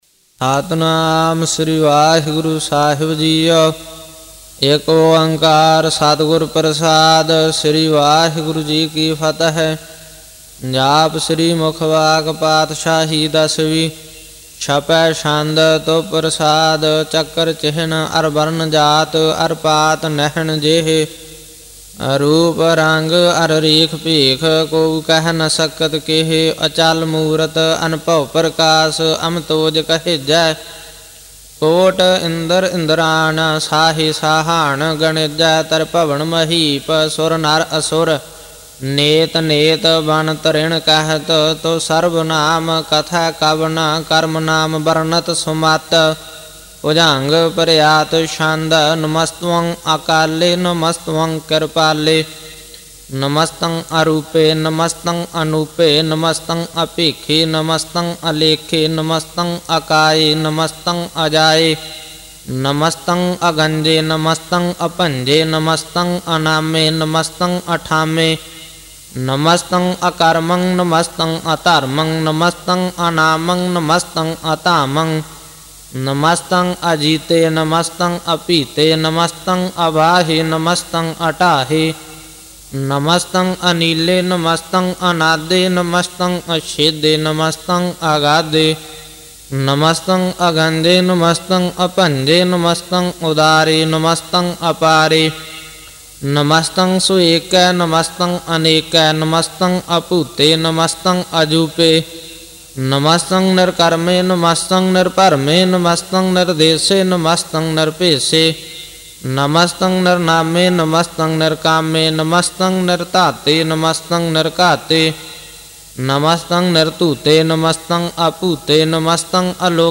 Gurbani Ucharan(Paath Sahib)
Album:Jaap.Sahib Genre: -Gurbani Ucharan Album Info